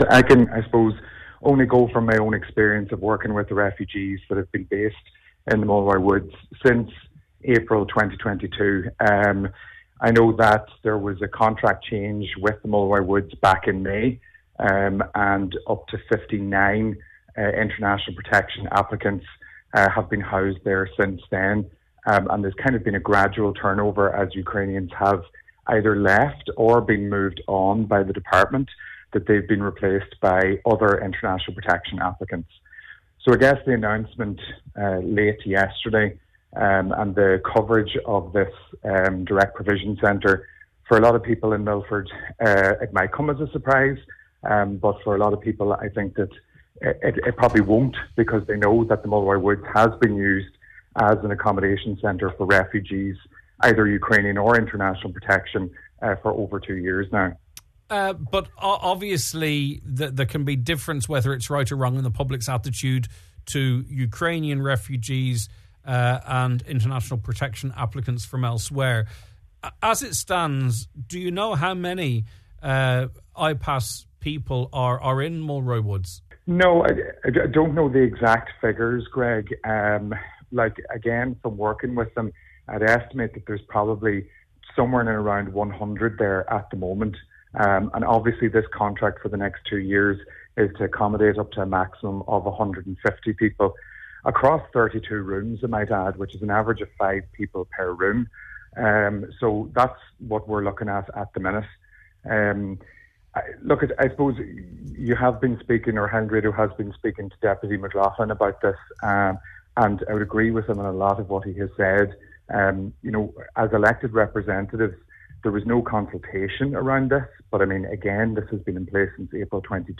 On today’s Nine til Noon Show, he said he agrees with much of what Deputy Padraig McLaughlin is saying, particularly in terms of the conditions under which people are living, and the lack of social facilities………